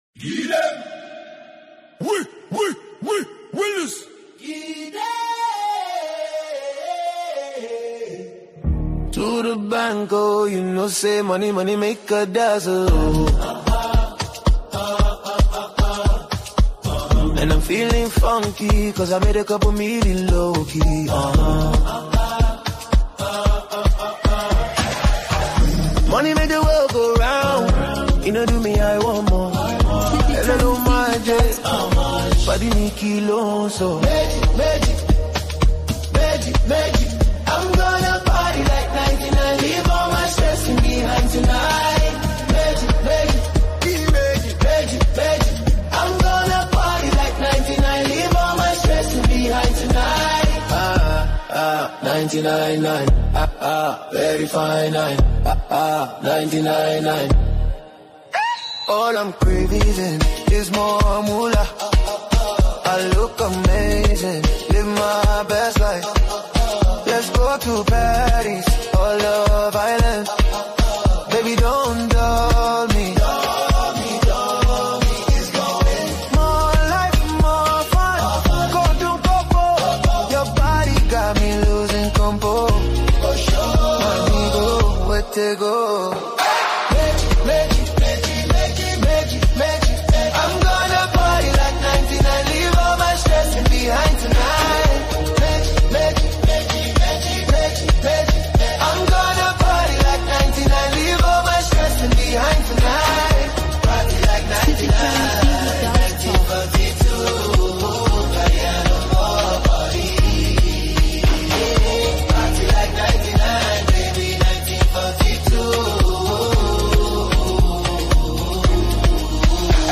With its infectious beat and captivating vocals